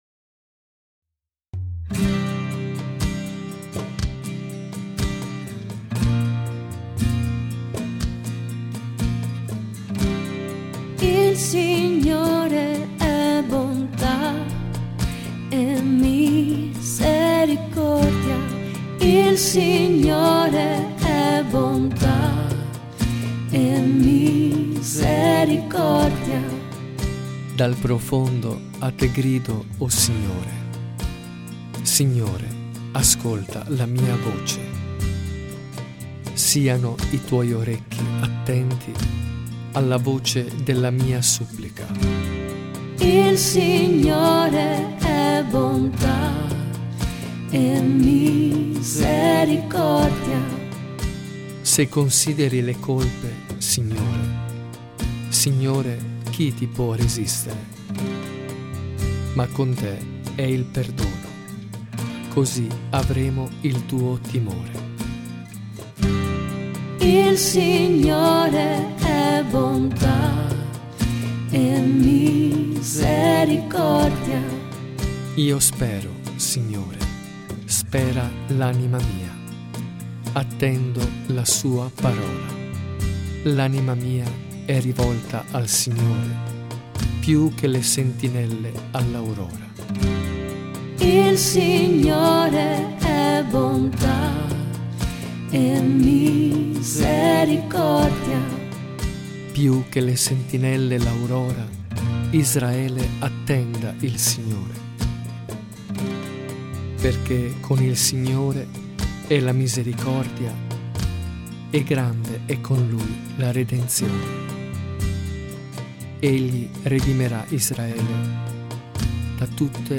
SALMO RESPONSORIALE
RITORNELLO CANTATO